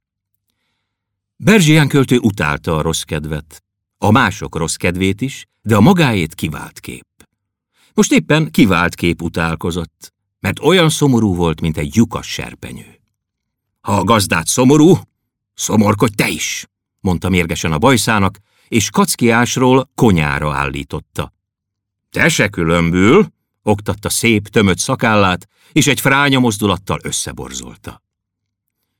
Keszult a Michel-Soundban
Album: Hangoskönyvek gyerekeknek